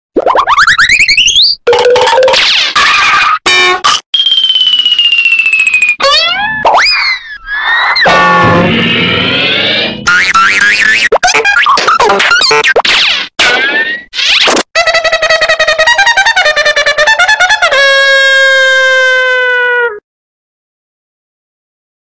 Goofy ah cartoon edit sound sound effects free download